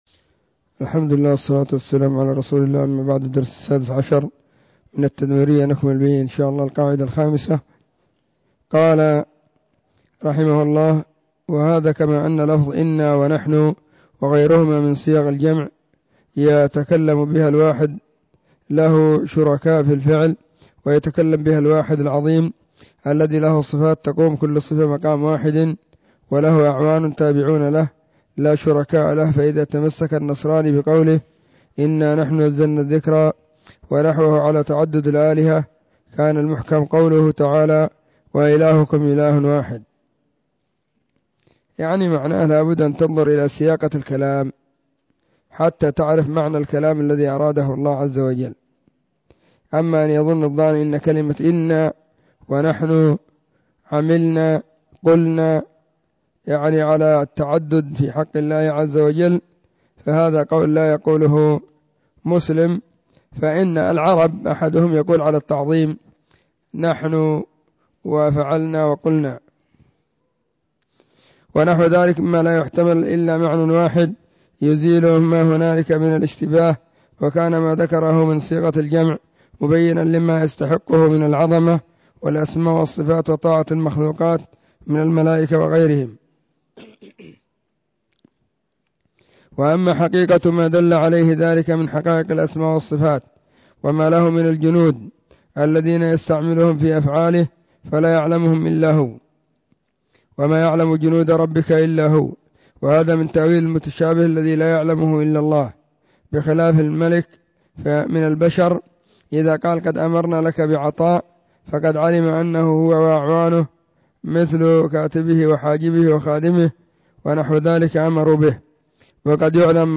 📢 مسجد الصحابة – بالغيضة – المهرة، اليمن حرسها الله.
العقيدة-التدمرية-الدرس-16.mp3